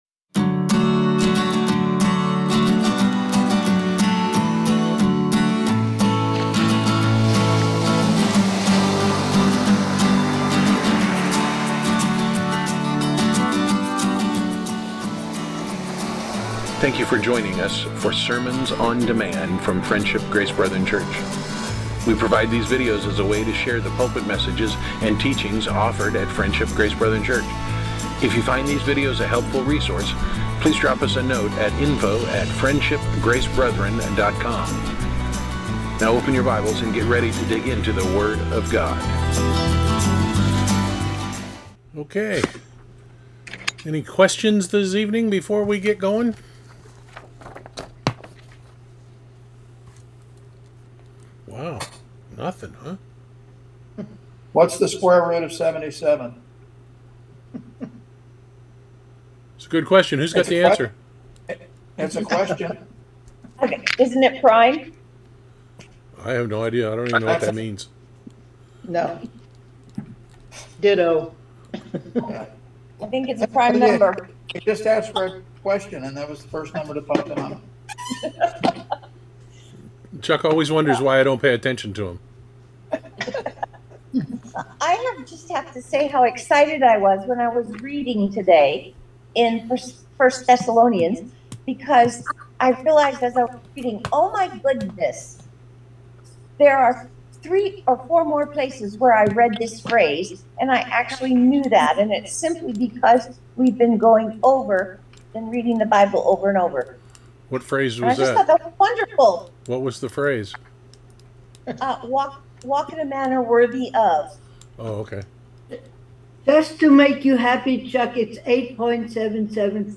Topic: Weekly Bible Reading Discussion